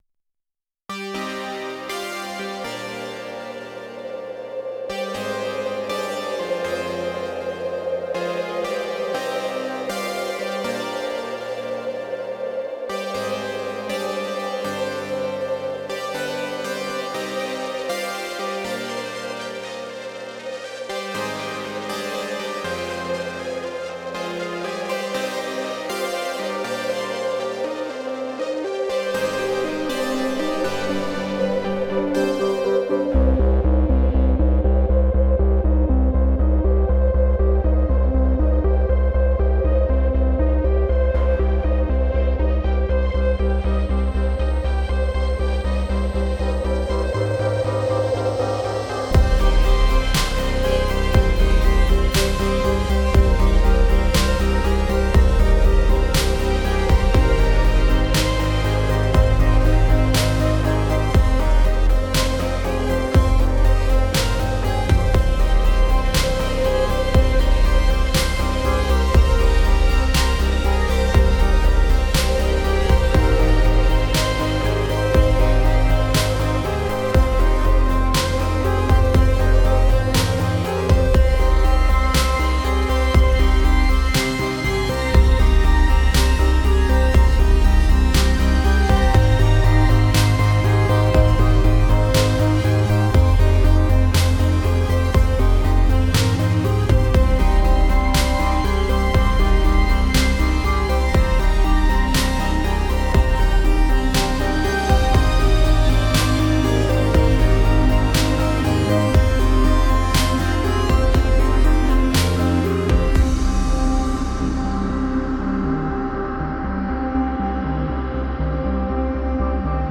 No Melody